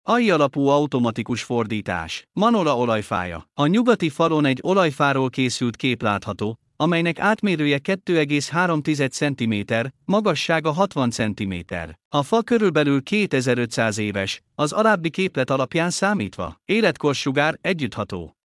Hangalapú idegenvezetés